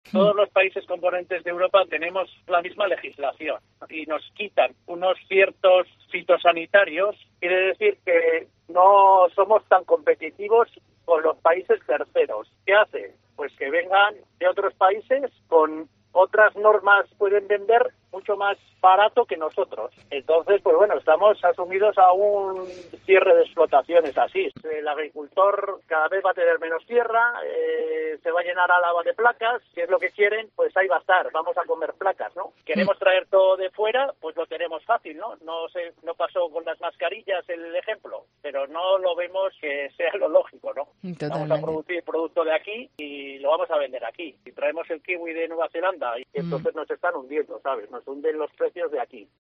agricultor alavés